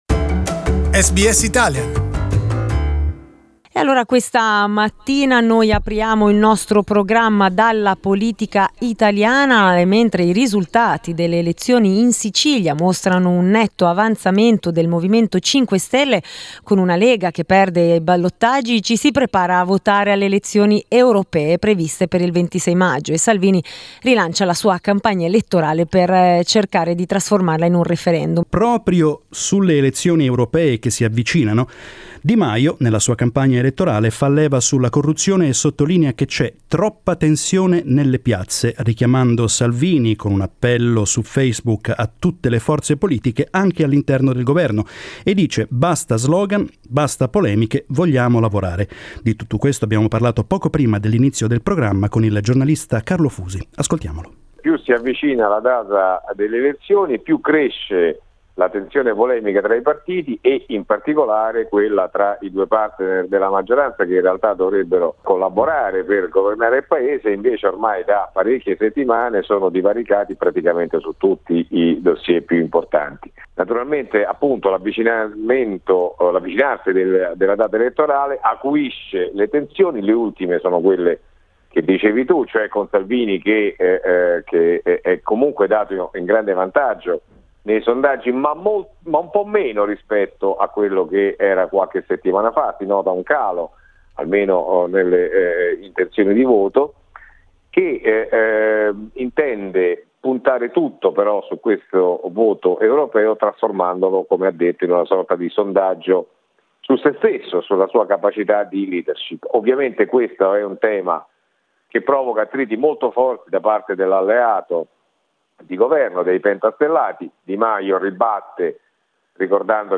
We talked about EU elections and other topics with Italian politics correspondent